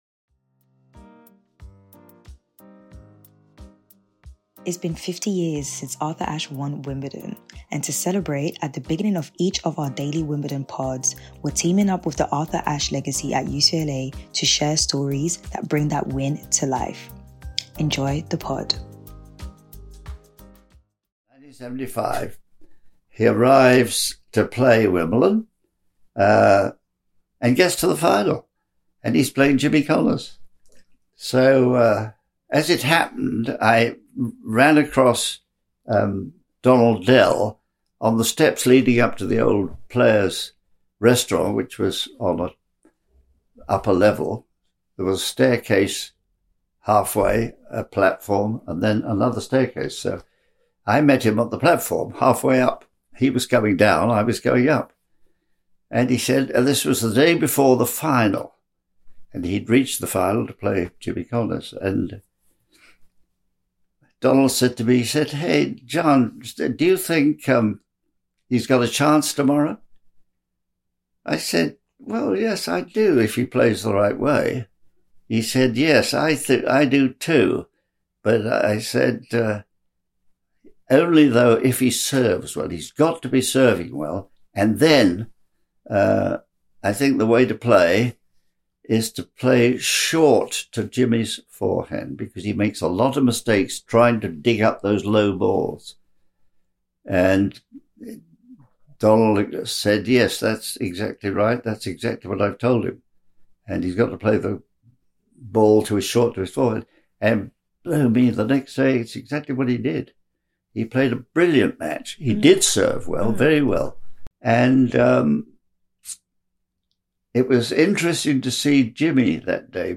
Listen out for a post-match press snippet from Shelton.